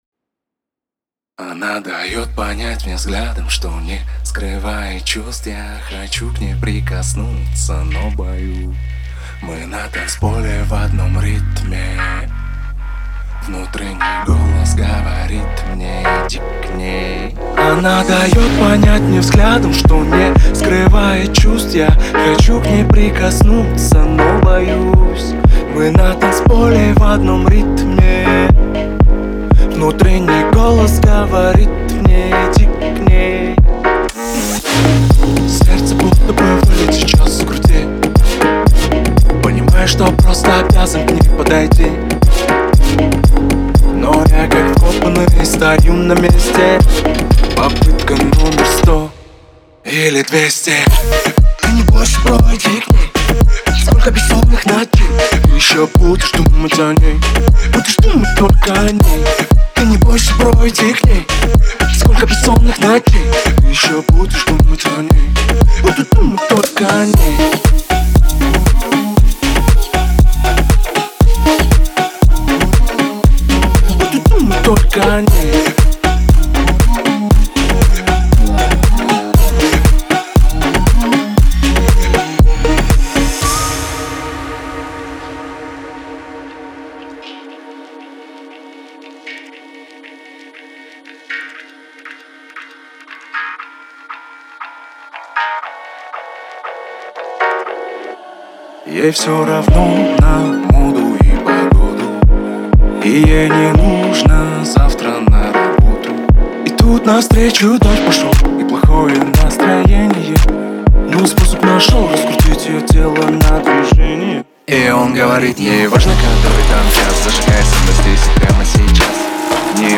• Жанр: Корейские песни